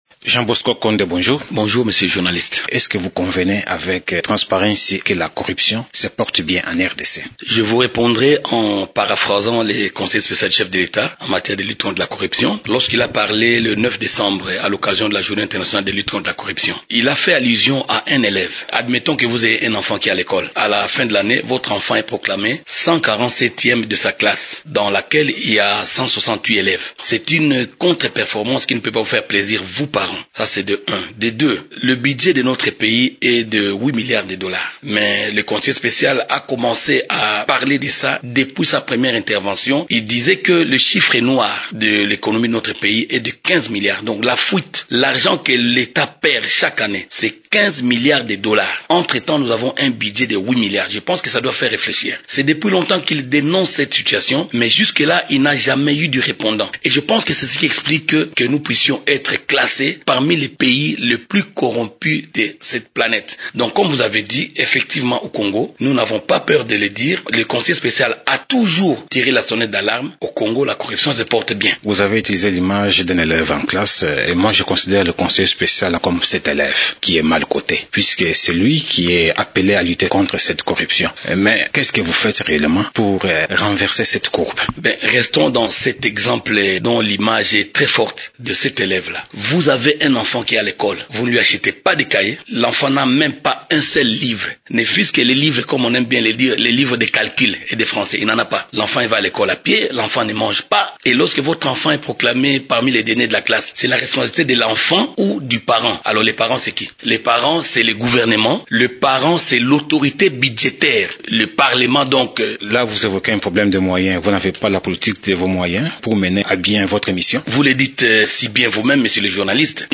Dans cette interview, il avance les raisons pour expliquer cet état des choses.